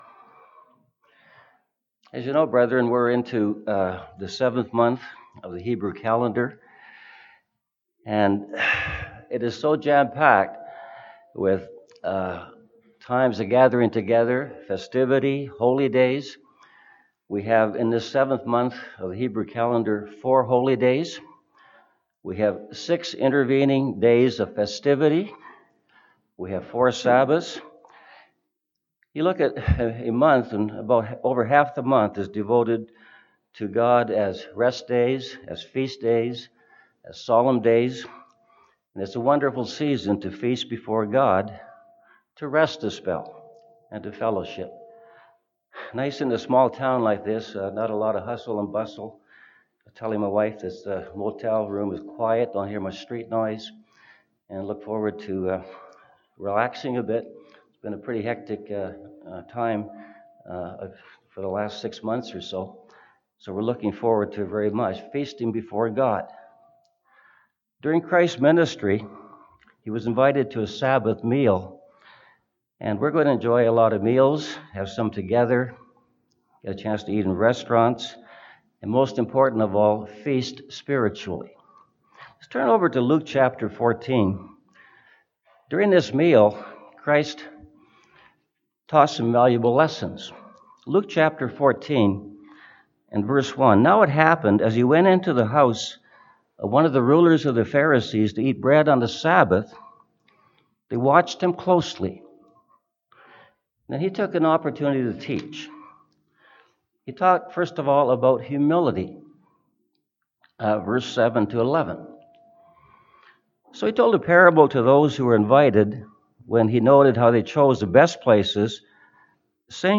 This sermon was given at the Drumheller, Alberta 2018 Feast site.